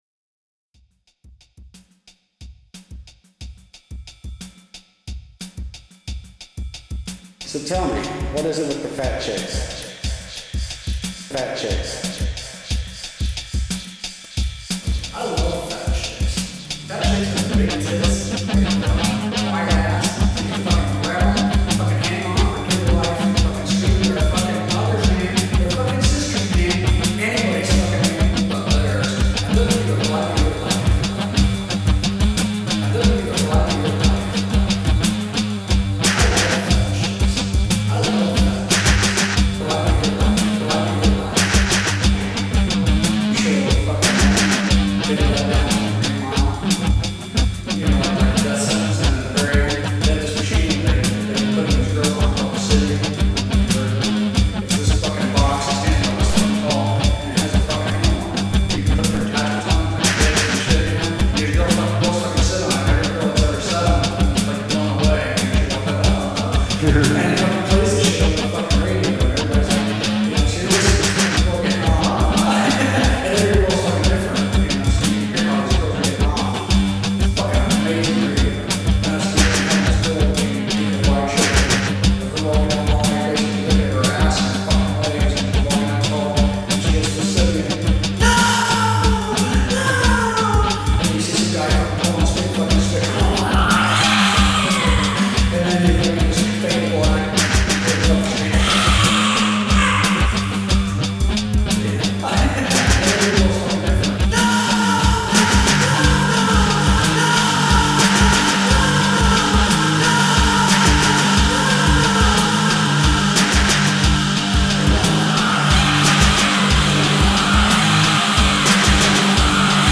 80's thrash metal
thrash metal